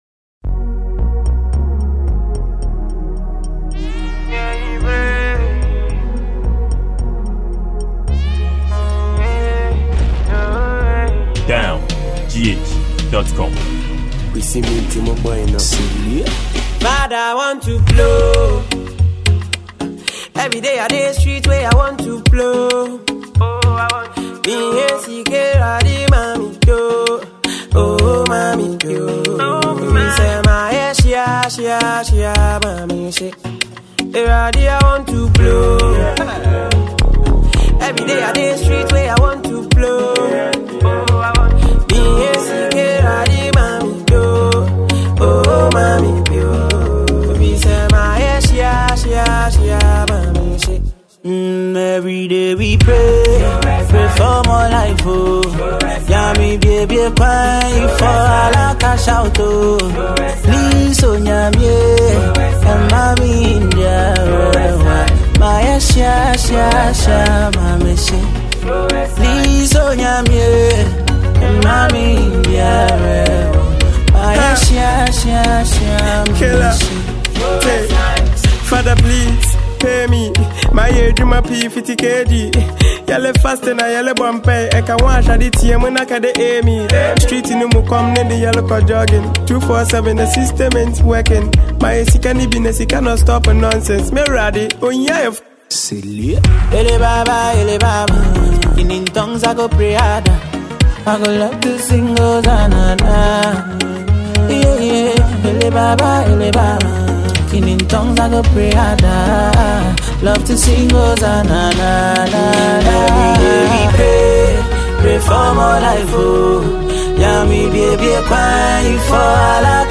Ghana Music
afobeat-highlife song